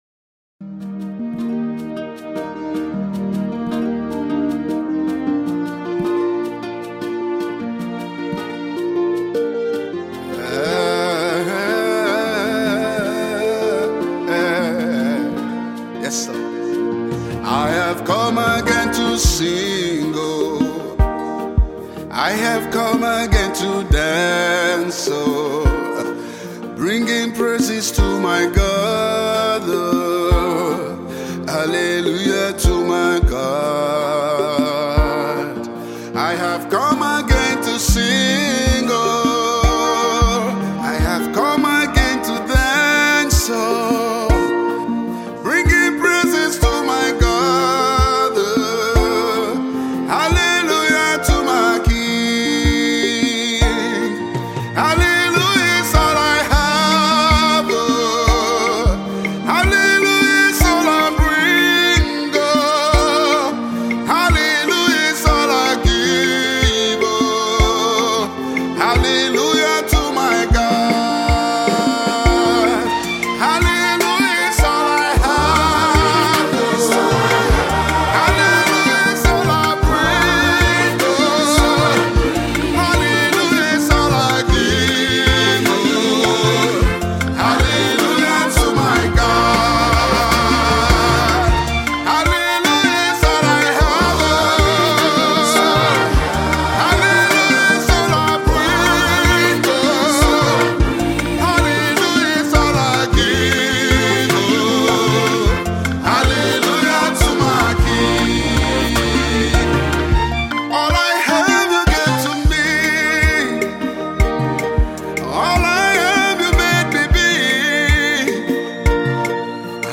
Its well rendered with modern and African melody.